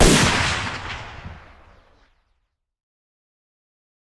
sniper_shot.wav